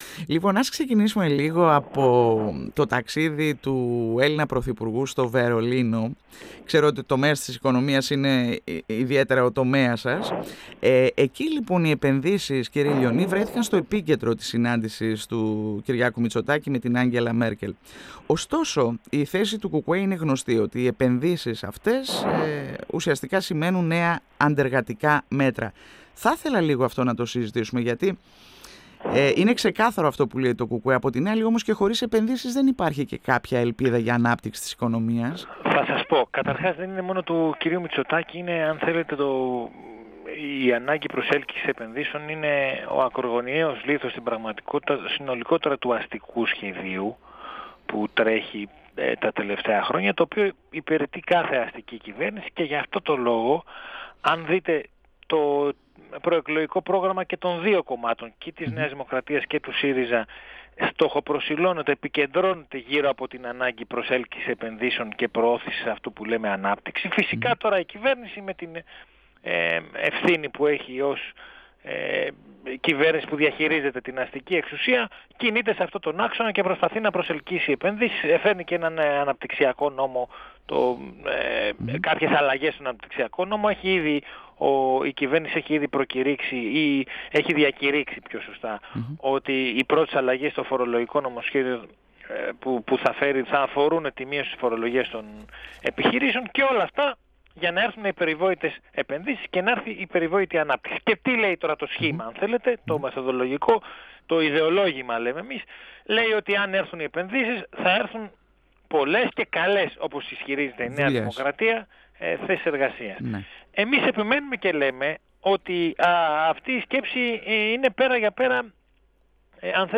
μιλώντας στον 102 fm της ΕΡΤ3